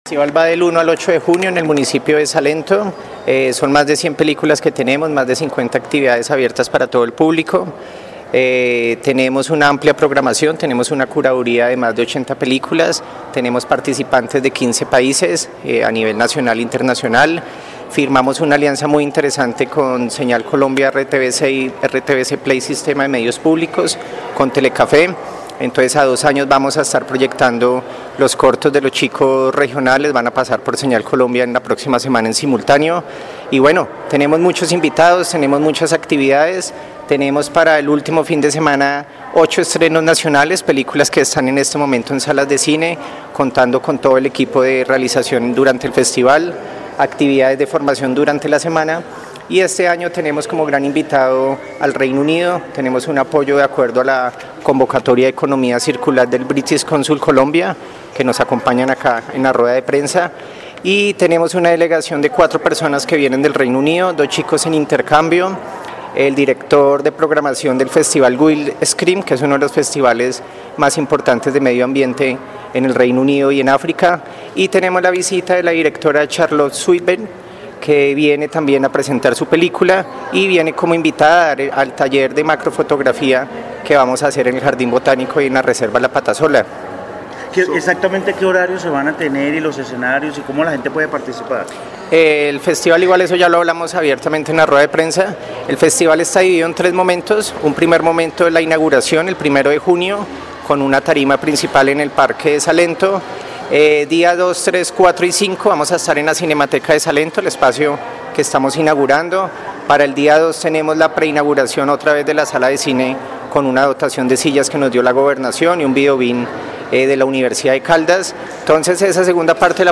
En Caracol Radio Armenia hablamos con